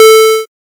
safe-3.ogg.mp3